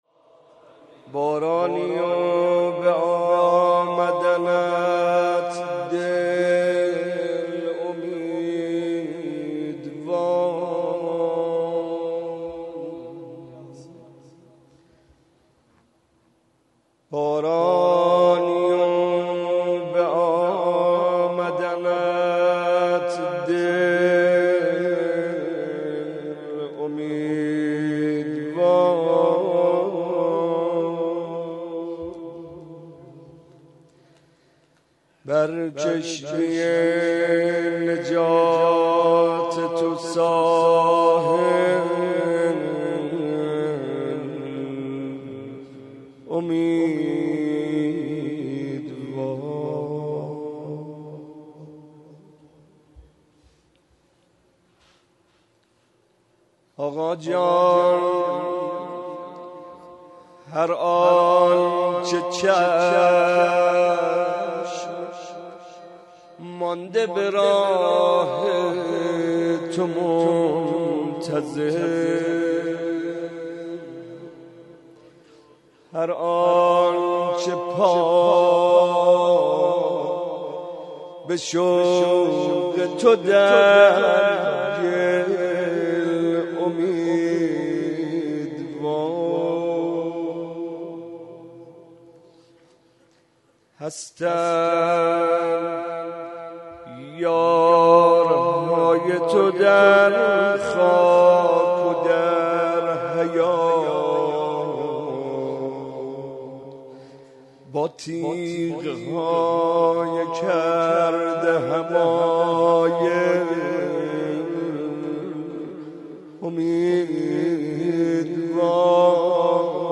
01.monajat.mp3